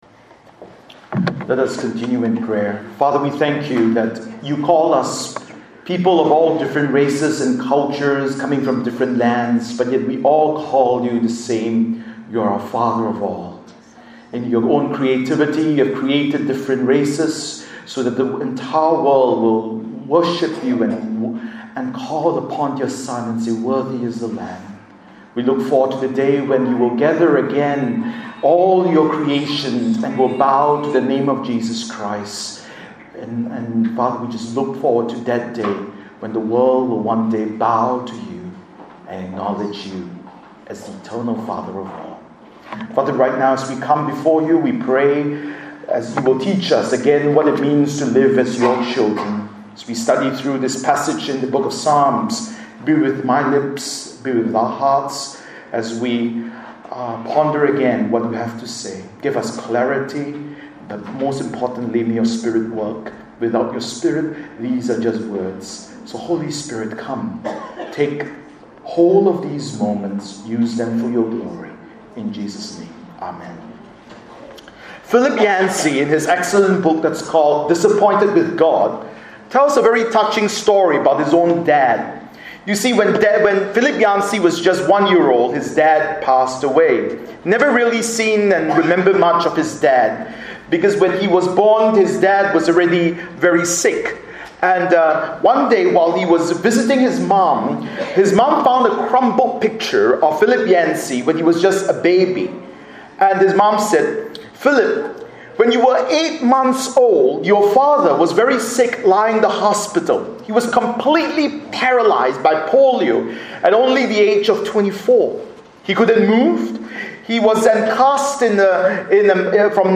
Bible Text: Psalm 78:1-8 | Preacher